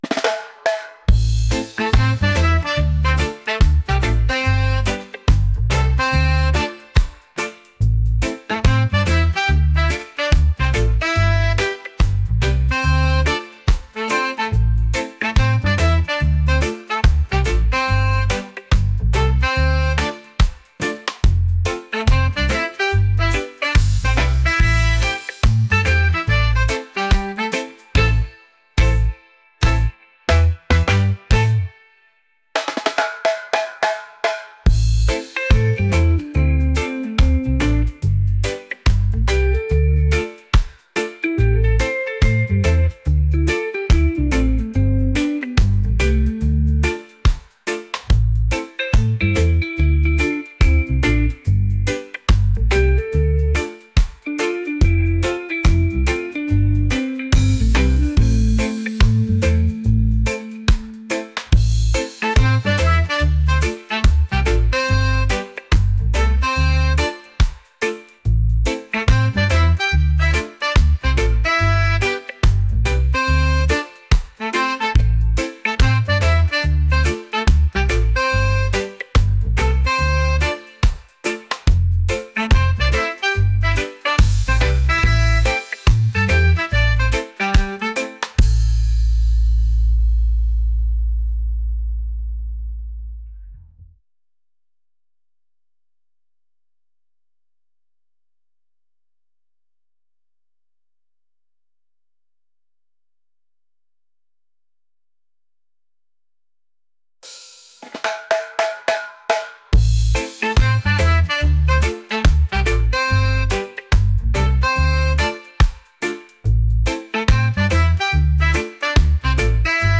reggae | soulful